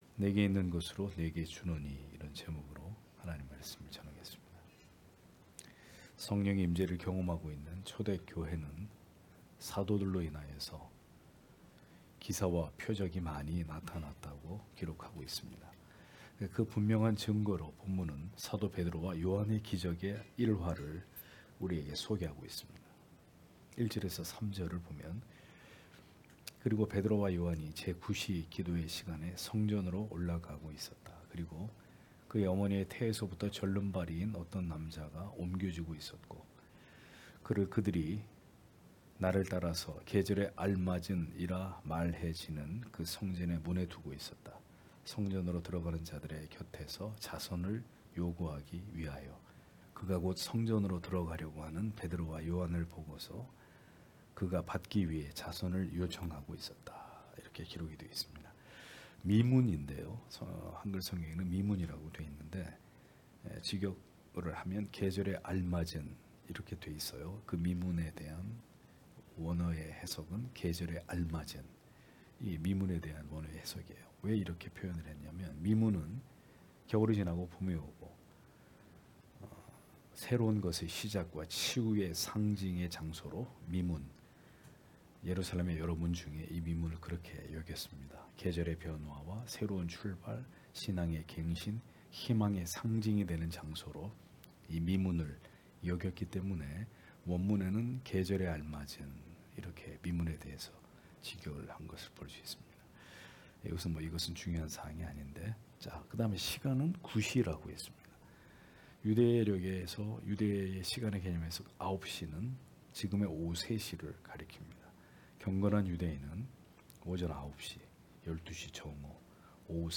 금요기도회 - [사도행전 강해 20] 내게 있는 것으로 네게 주노니 (행 3장 1-10절)